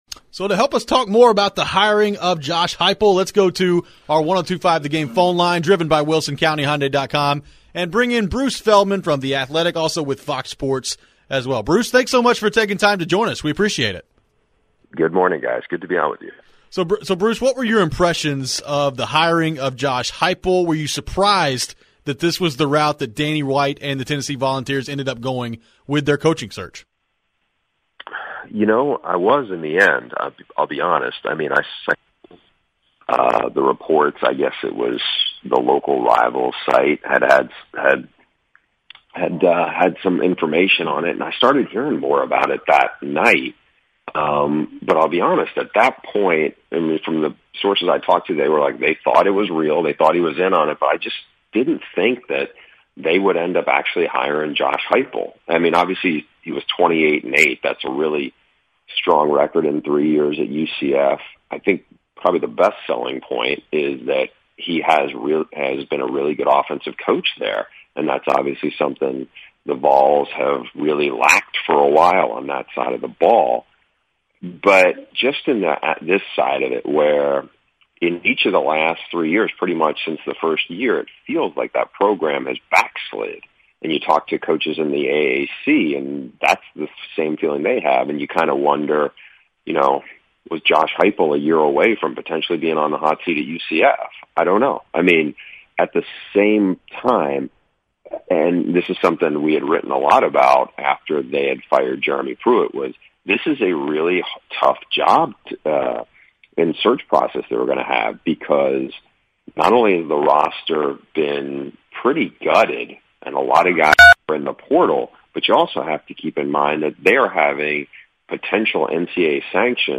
take some reactionary calls from listeners. Plus, Preds radio analyst Hal Gill joins the show and the guys react to the breaking Deshaun Watson news.